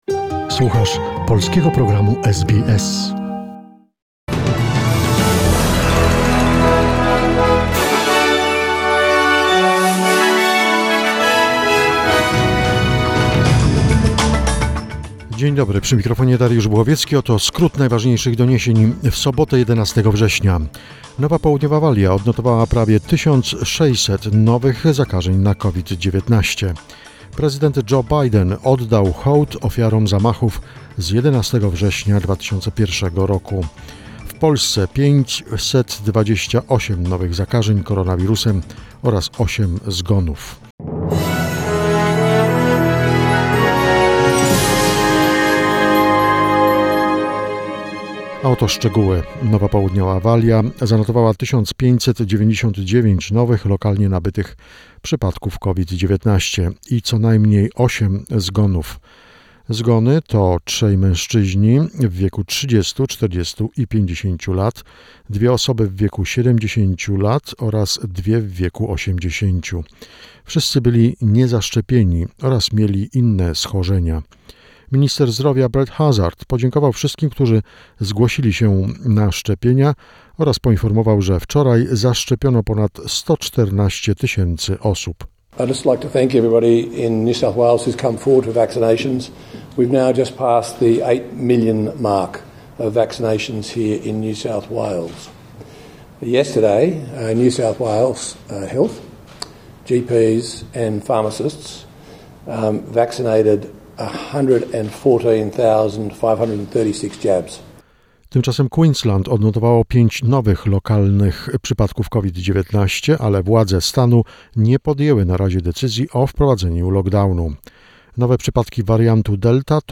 SBS News Flash in Polish, 11 September 2021